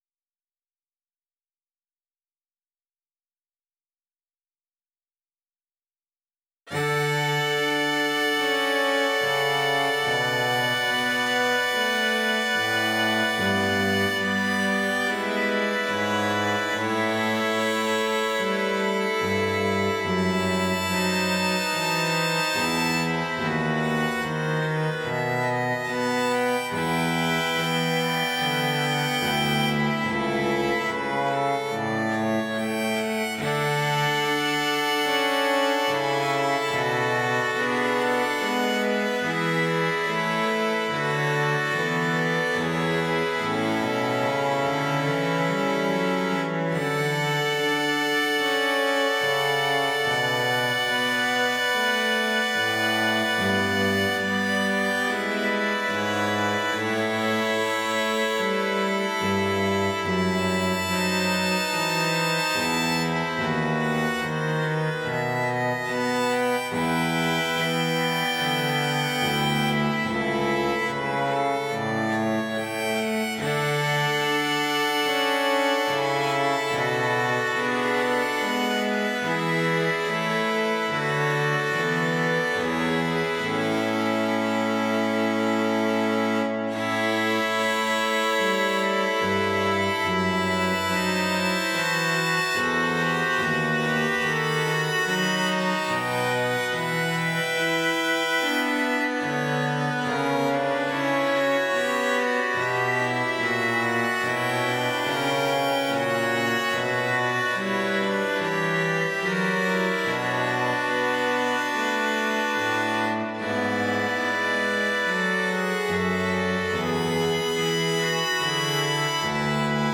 Barroco
tranquilidad
aria
paz
relajación
sintetizador